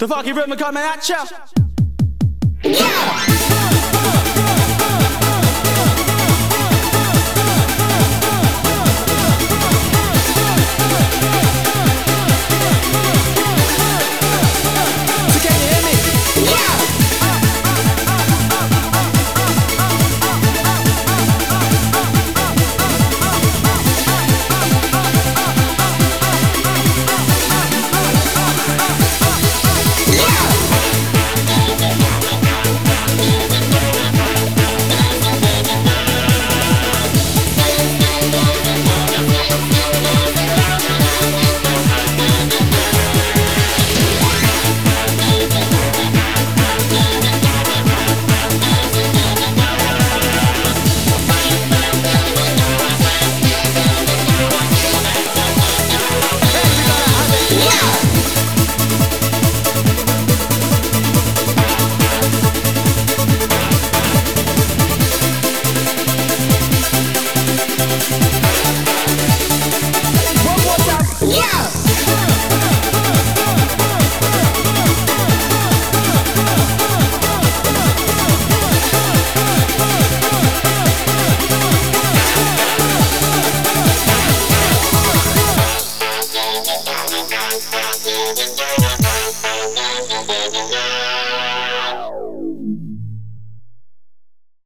BPM140
Better quality audio.